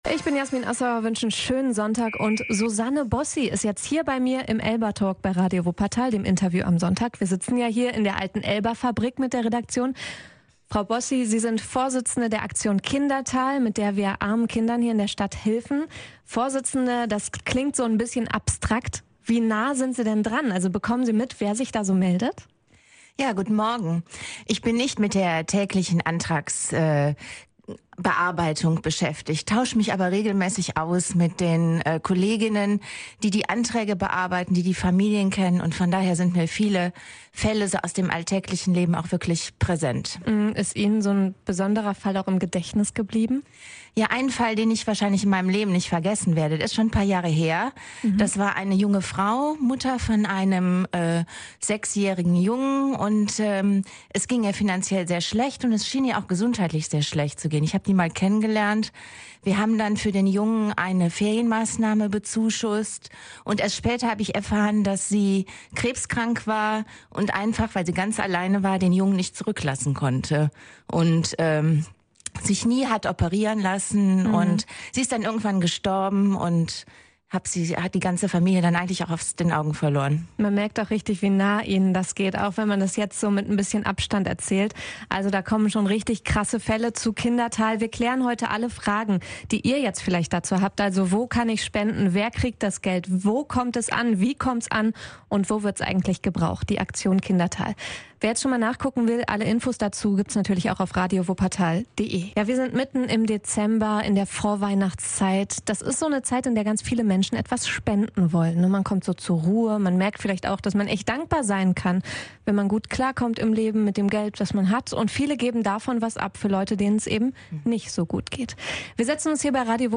ELBA Talk